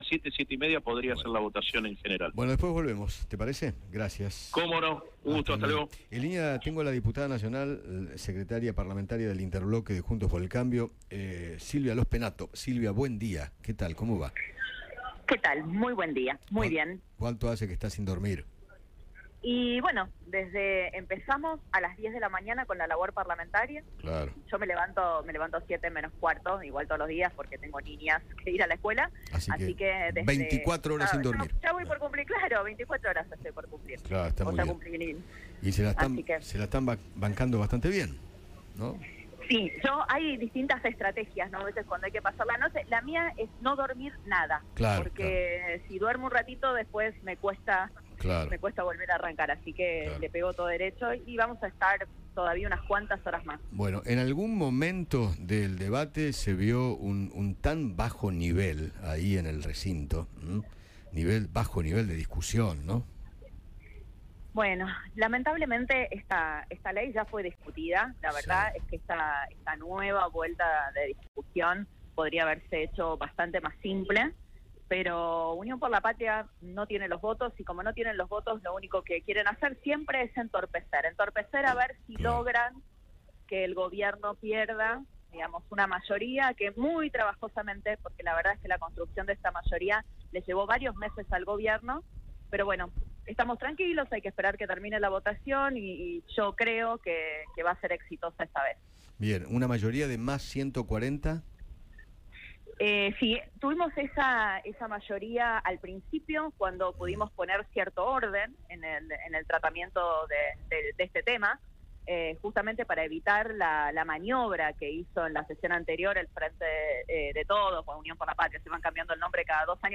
Silvia Lospennato, diputada del PRO, dialogó con Eduardo Feinmann sobre el debate de la Ley Bases en la Cámara Baja.